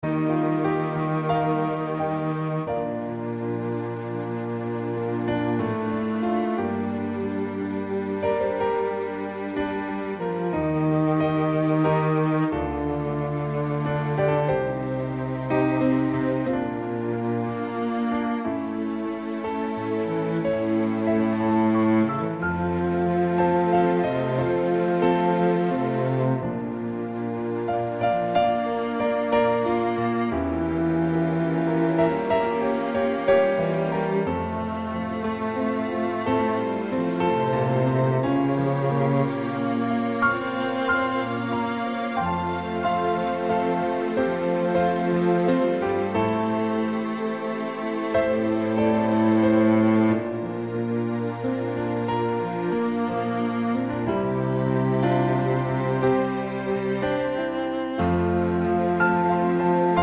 Low Female Key